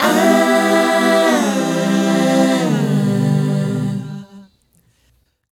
Aaah Group 086 1-G.wav